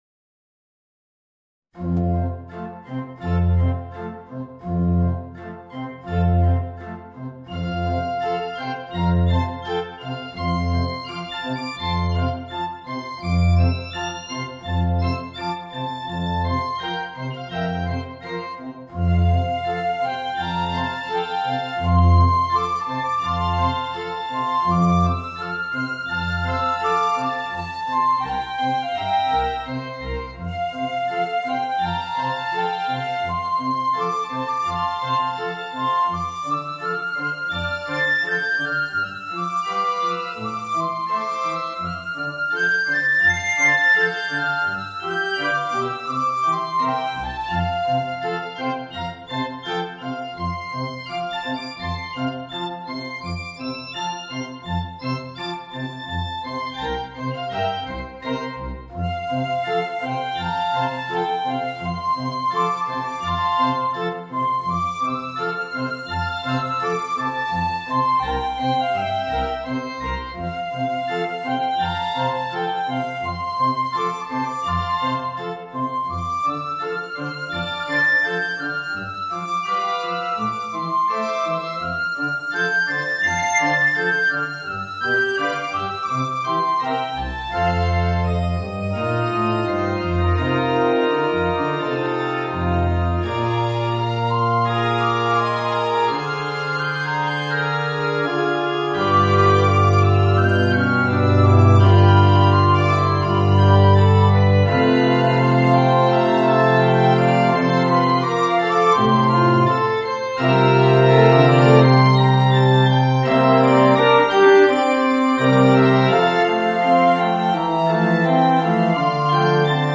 Voicing: Piccolo and Organ